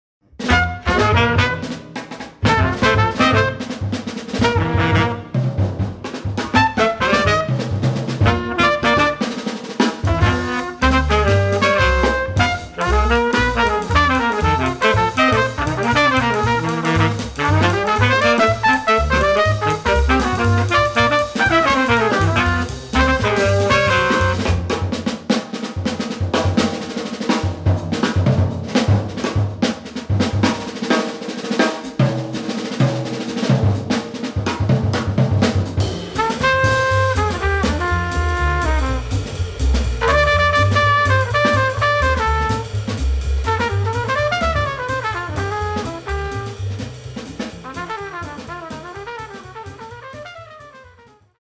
trumpet
sax
bass
drums